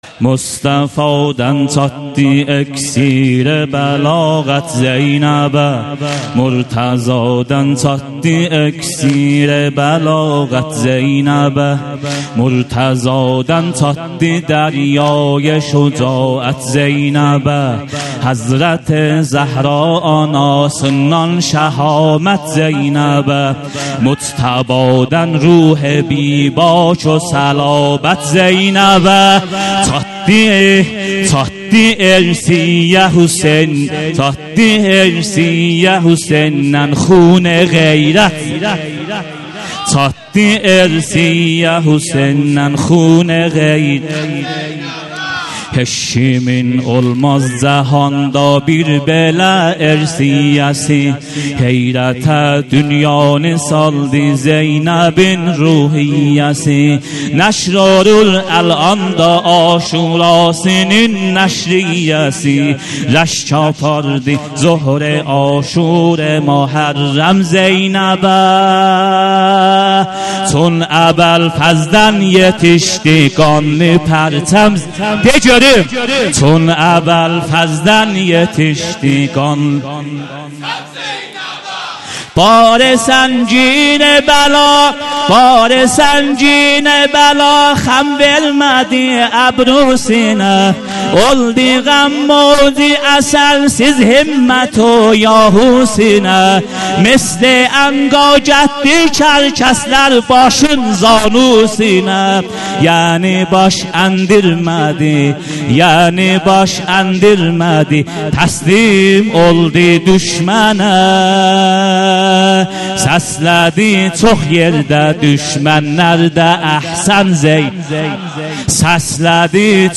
هیأت محبان اهل بیت علیهم السلام چایپاره